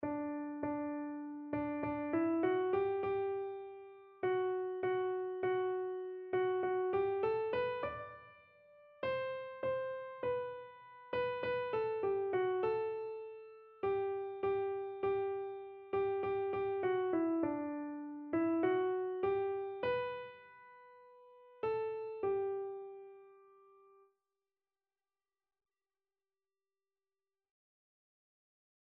Christian Christian Keyboard Sheet Music O Love That Wilt Not Let Me Go
Free Sheet music for Keyboard (Melody and Chords)
4/4 (View more 4/4 Music)
G major (Sounding Pitch) (View more G major Music for Keyboard )
Keyboard  (View more Easy Keyboard Music)
Classical (View more Classical Keyboard Music)